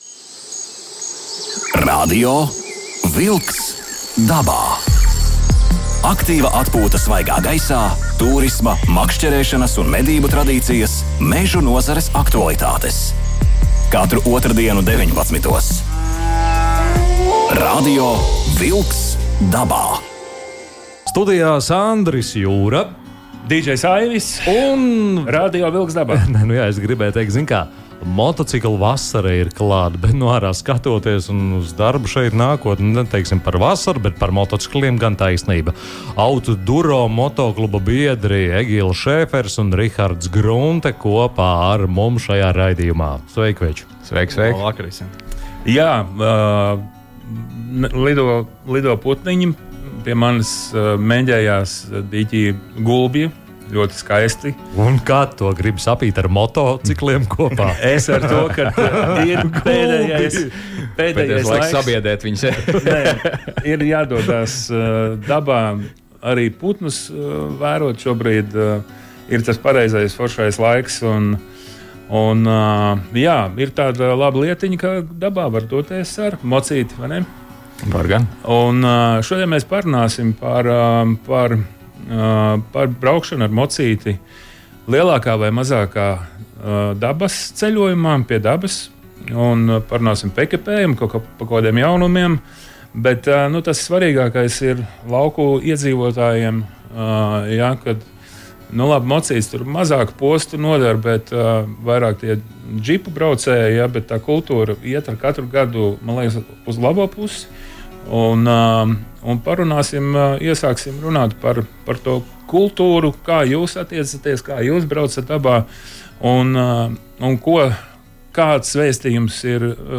Unikāla reportāža no Āfrikas kontinenta! – Radio vilks dabā – Podcast
speciālreportāžā no Zanzibāras salas (attēlā), kura atrodas zem ekvatora, Indijas okeāna apskalota.